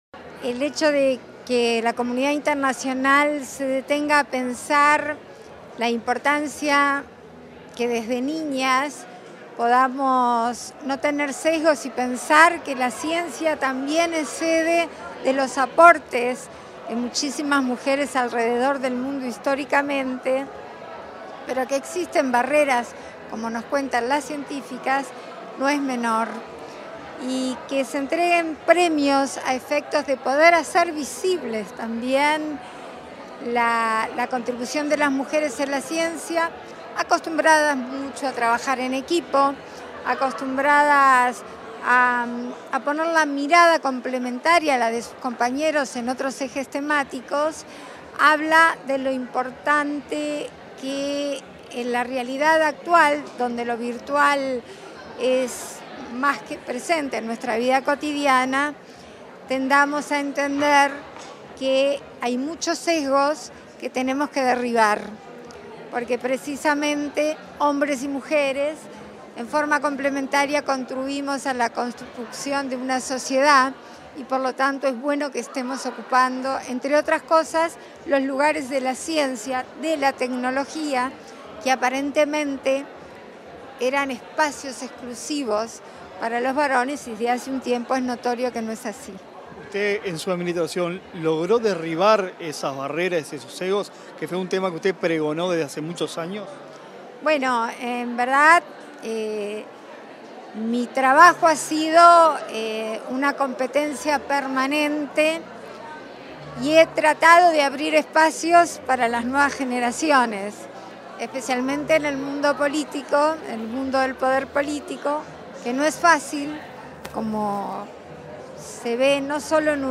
Entrevista a la vicepresidenta de la República, Beatriz Argimón